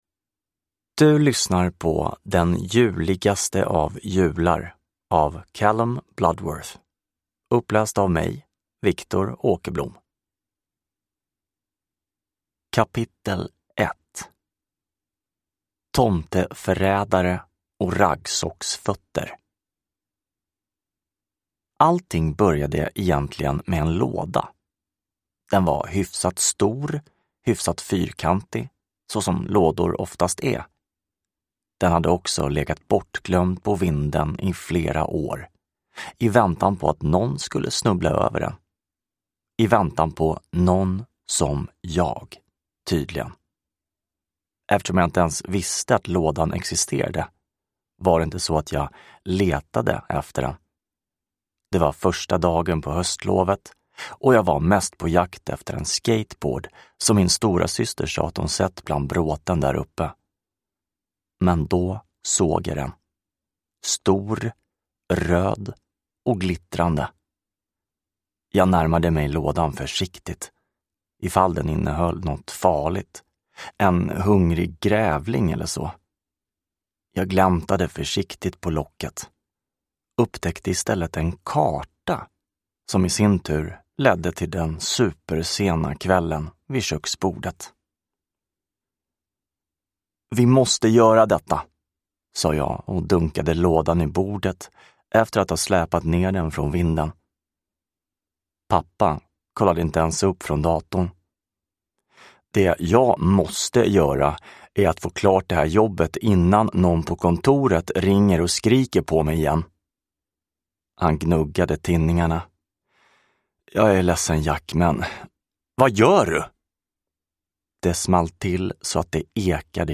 Den juligaste av jular : en berättelse i 24 kapitel – Ljudbok